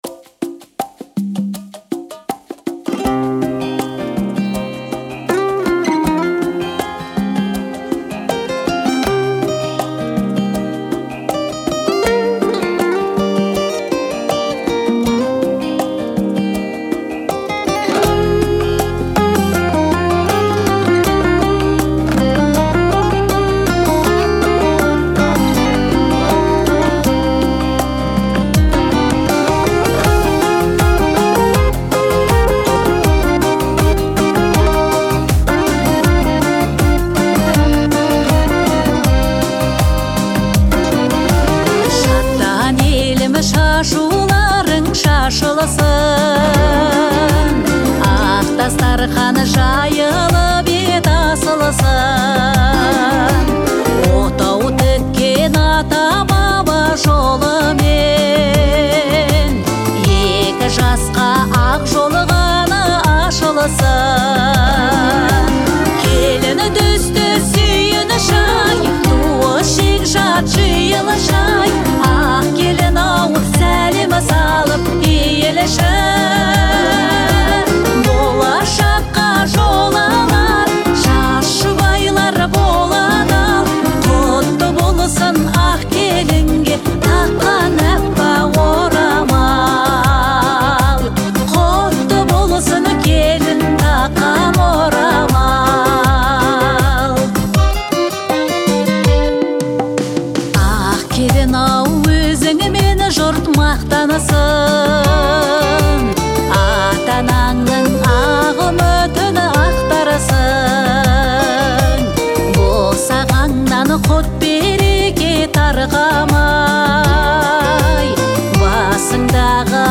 выразительным вокалом и эмоциональной интерпретацией